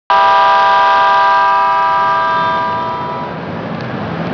〜車両の音〜
E655系 警笛
ごく普通の音です。